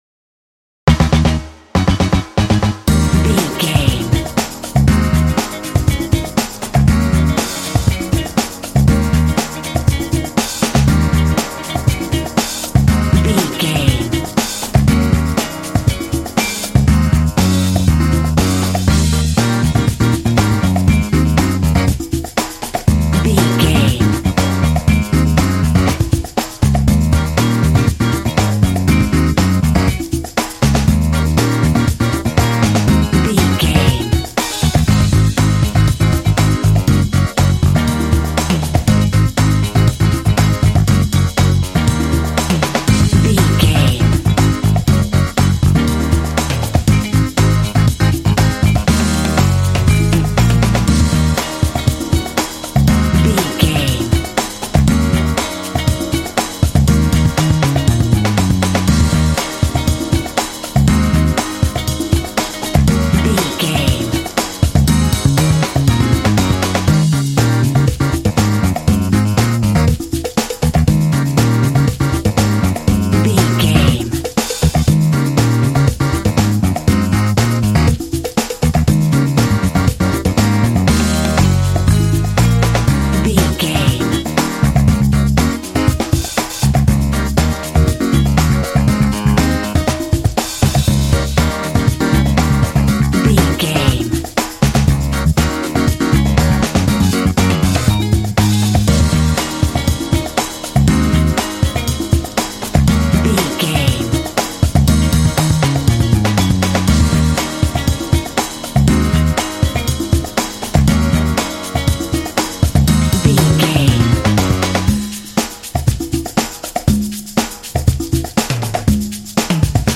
Aeolian/Minor
E♭
groovy
energetic
percussion
drums
piano
electric guitar
bass guitar